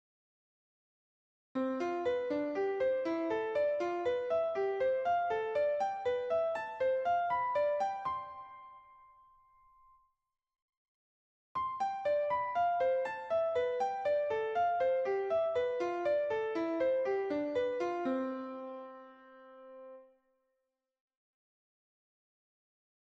Improvisation Piano Jazz
Licks avec des quartes
Sur Gamme Majeure
lick_quartes.mp3